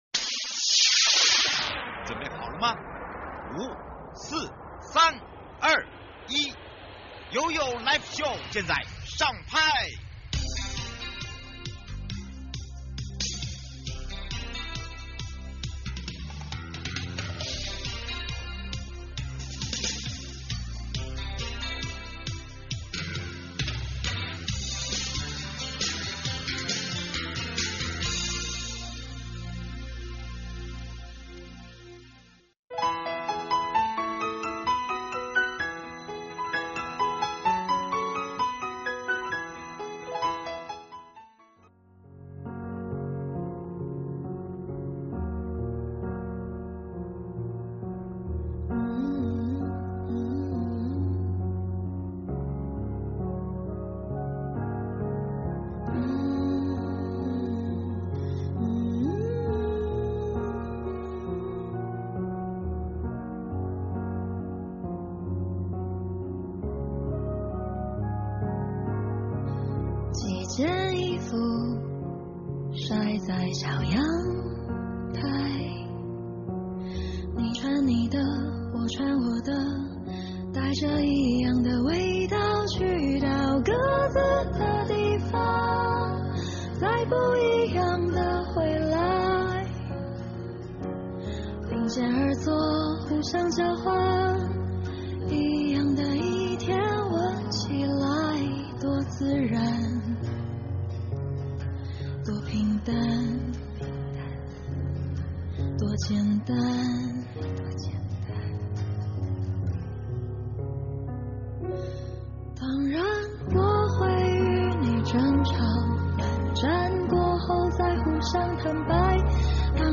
受訪者： 花東縱谷管理處